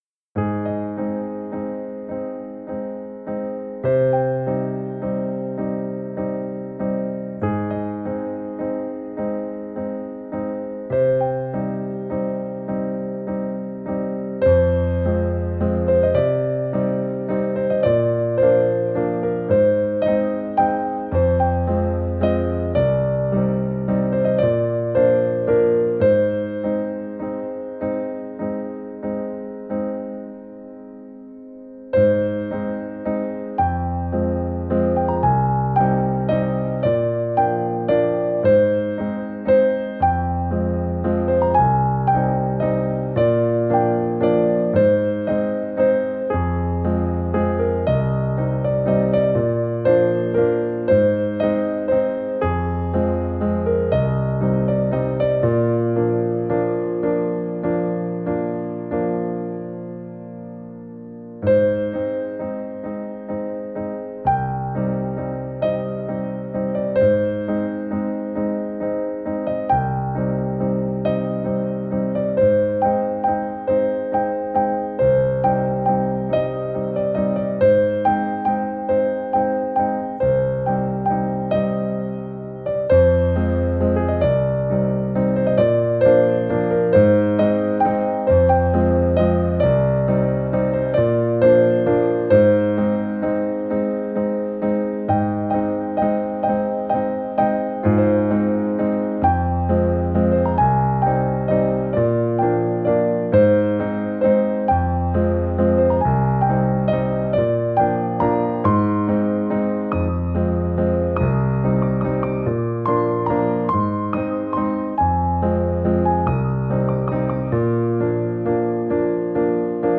Piano Tutorial/Cover
Piano Cover